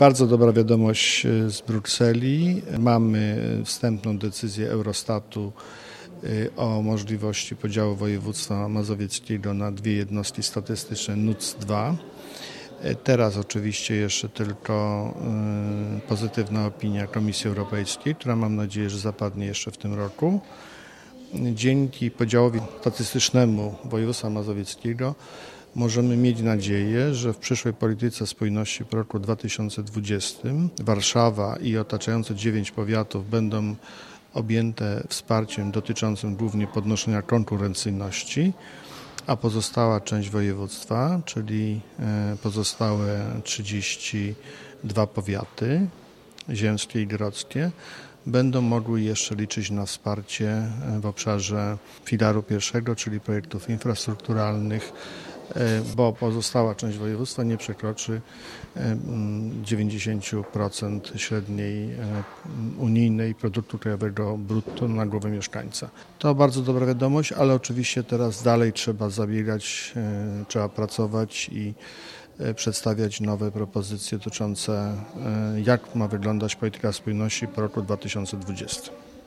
35d0d-mp3_marszalek-adam-struzik-o-decyzji-eurostatu-w-sprawie-nuts-2.mp3